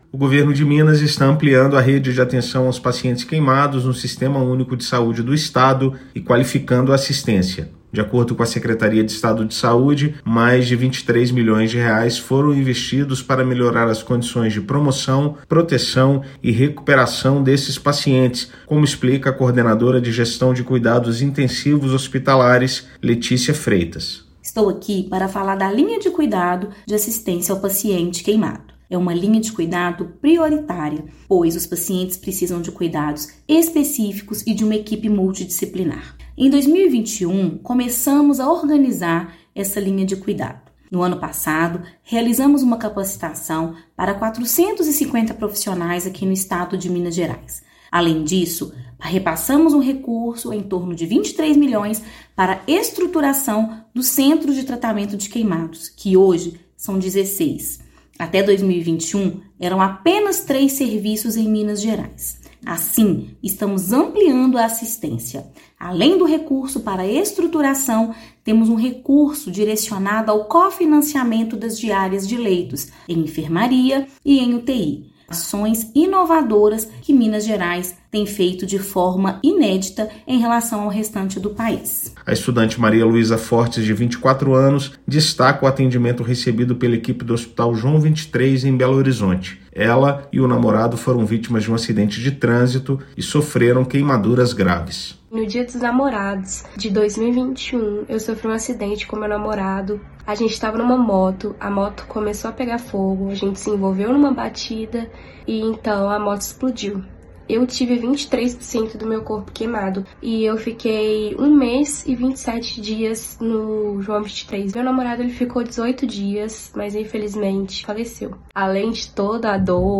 Atendimento oportuno, qualificado e próximo de casa favorece recuperação e evita sequelas; governo estadual também abre mais leitos e aposta na capacitação de servidores em todo o estado. Ouça matéria de rádio.